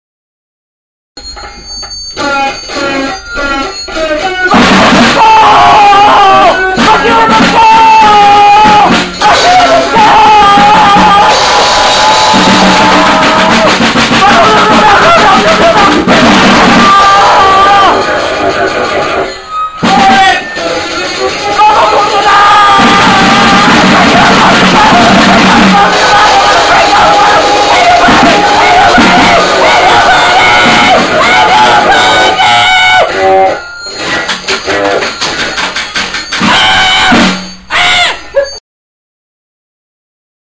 penn state's premiere grindcore band.